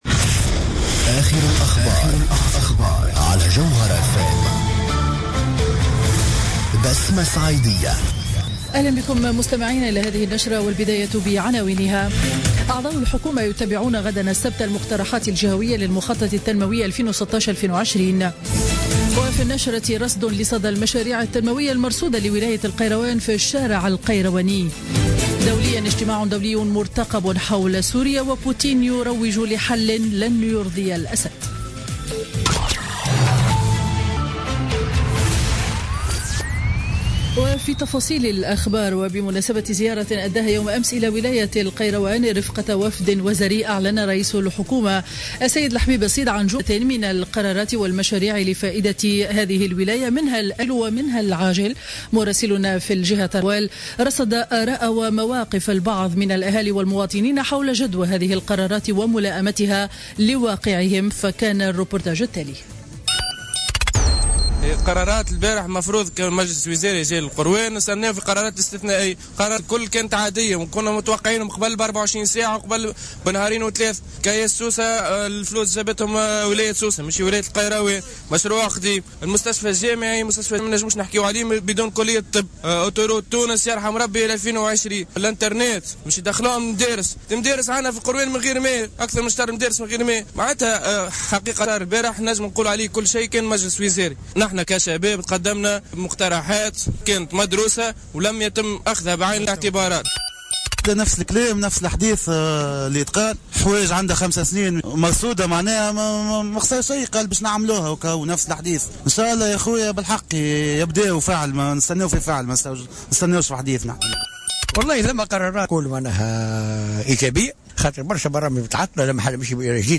نشرة أخبار منتصف النهار ليوم الجمعة 18 ديسمبر 2015